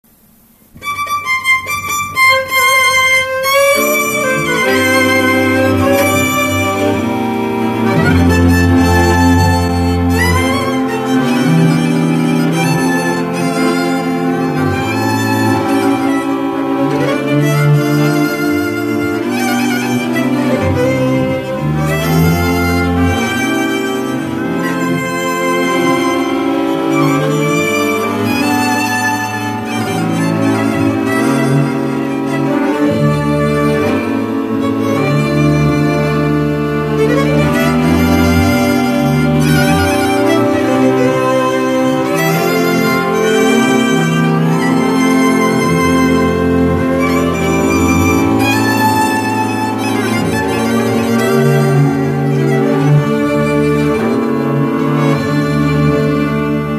Dallampélda: Hangszeres felvétel
Erdély - Kolozs vm. - Bánffyhunyad
hegedű
kontra
cselló
Stílus: 6. Duda-kanász mulattató stílus